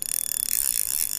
reel_01.ogg